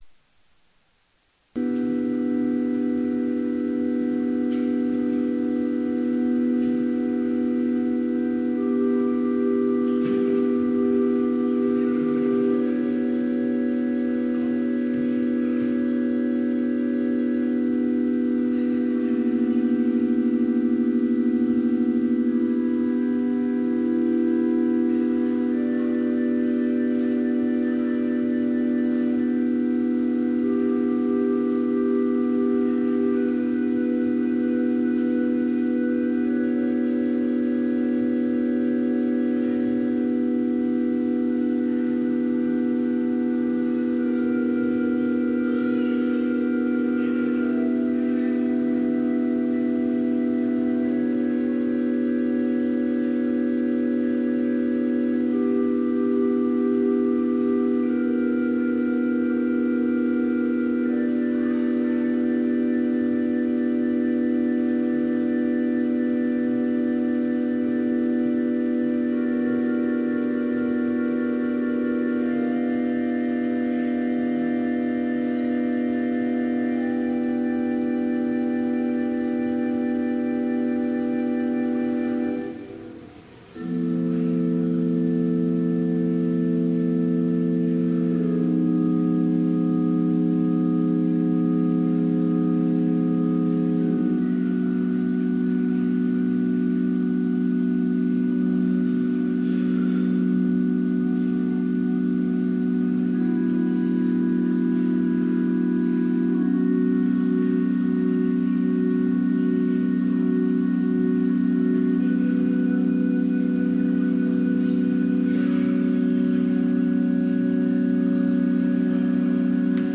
Complete service audio for Chapel - February 14, 2017
Order of Service Prelude Hymn 520, vv. 1-4, Soldiers of Christ, Arise Reading: I Corinthians 9:24-27 Devotion Prayer Hymn 520, From strength… Blessing Postlude